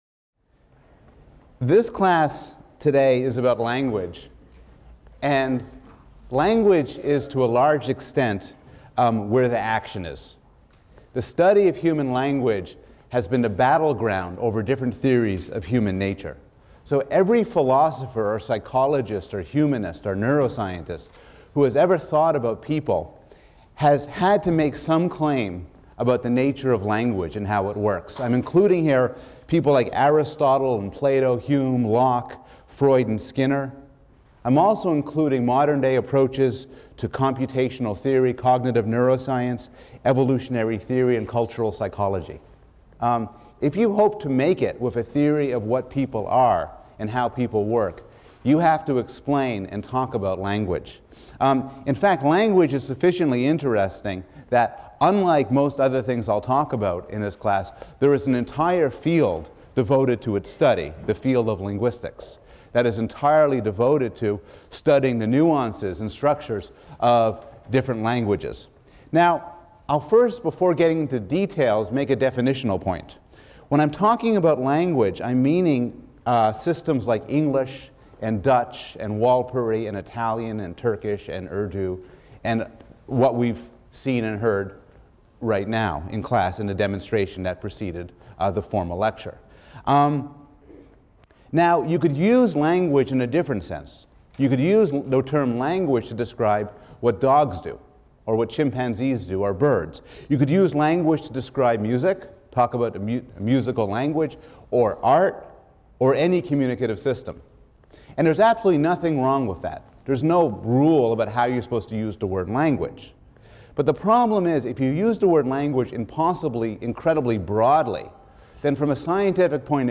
PSYC 110 - Lecture 6 - How Do We Communicate?: Language in the Brain, Mouth and the Hands | Open Yale Courses
[Before class started, Professor Bloom had several bilingual students give demonstrations of non-English speech.]